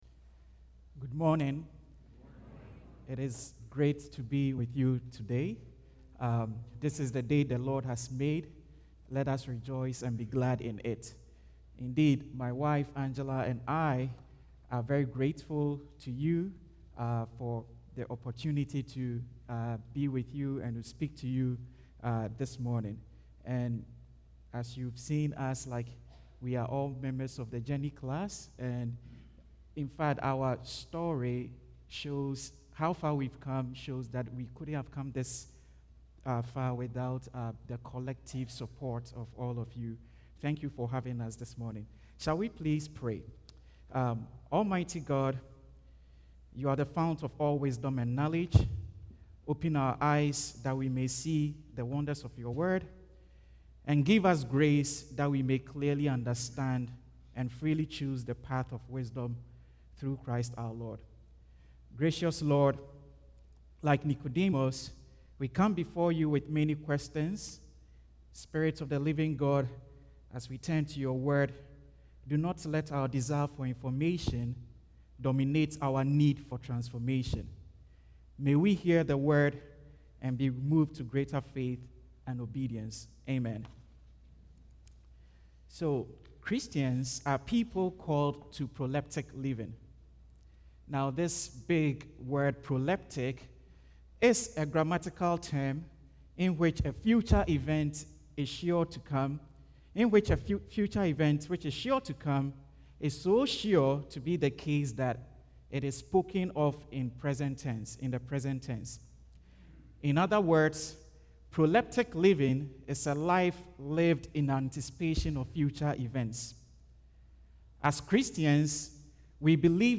Passage: Revelation 7:9-17 Service Type: Guest Preacher